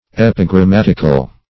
Search Result for " epigrammatical" : The Collaborative International Dictionary of English v.0.48: Epigrammatic \Ep`i*gram*mat"ic\, Epigrammatical \Ep`i*gram*mat"ic*al\, [L. epigrammaticus: cf. F. ['e]pigrammatique.] 1.
epigrammatical.mp3